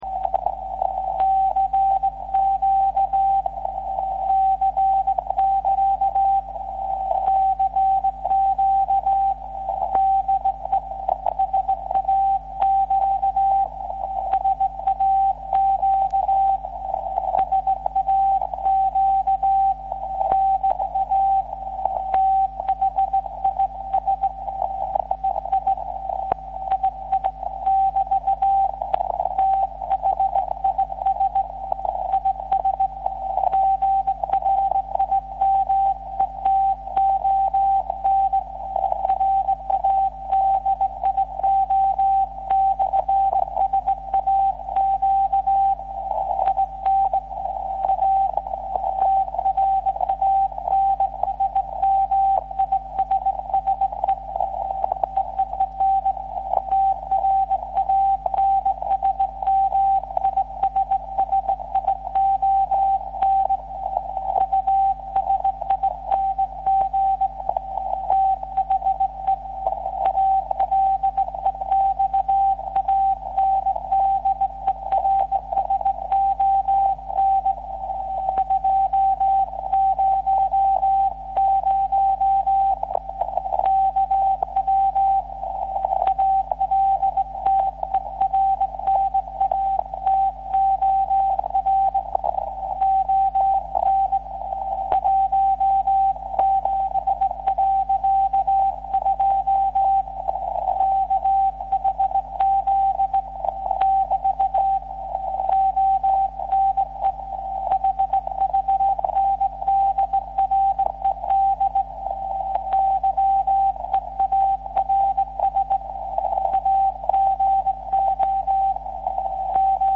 Am Sonntag, 03. Juli 2011 sendet anlässlich des Alexanderson Day um 09:00 Uhr UTC und 12:00 Uhr UTC der VLF-Sender SAQ in Grimeton / Schweden auf der VLF-Frequenz 17.2 kHz seinen jährliche Grußbotschaft.